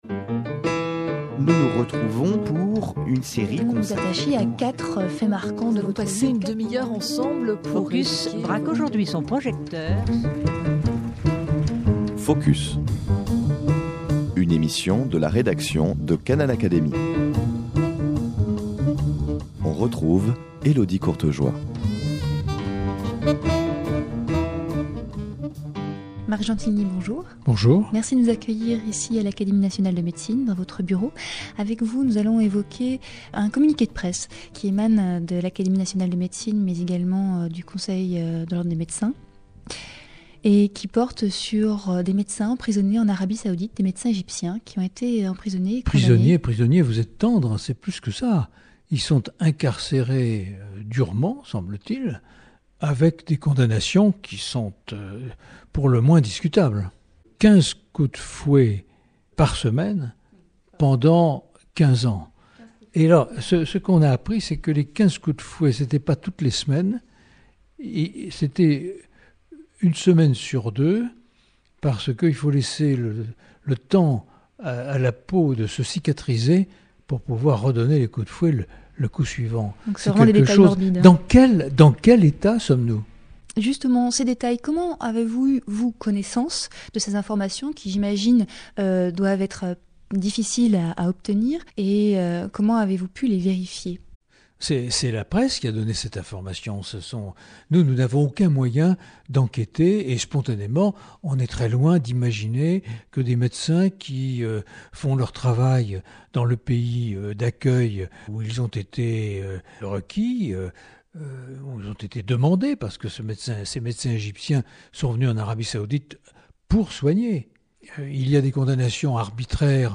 avec Marc Gentilini, président de l’Académie nationale de médecine
Écoutez Marc Gentilini, président de l'Académie nationale de médecine et membre de la Commission nationale consultative des droits de l’homme.